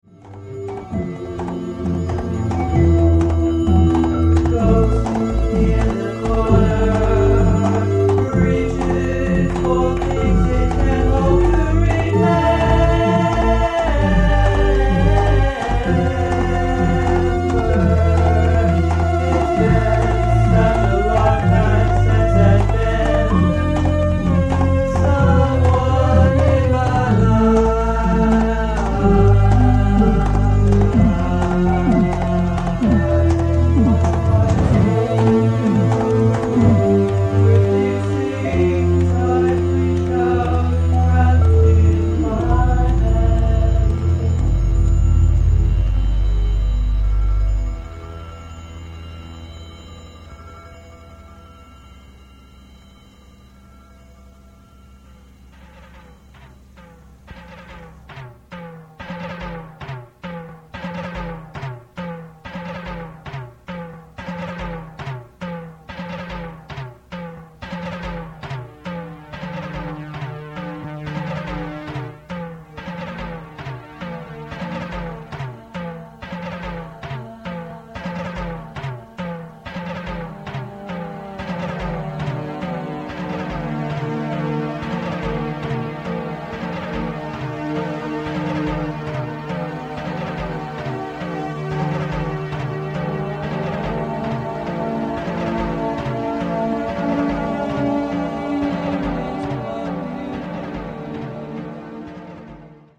Recorded in 1988 and 1989 in my home studio in San Francisco
experiments in Eno-esque songs